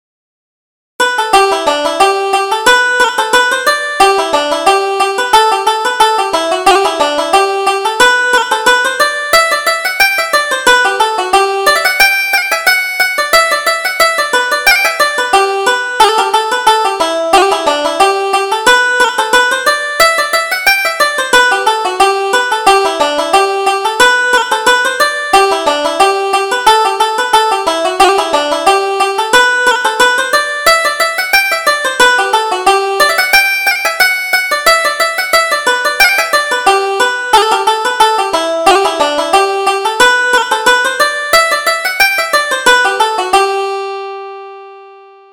Reel: Far from Home